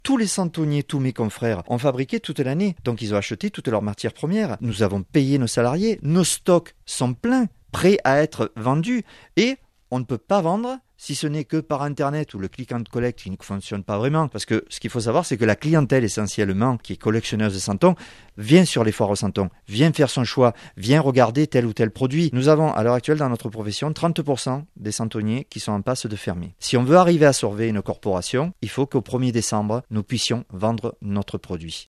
Reportage Sud Radio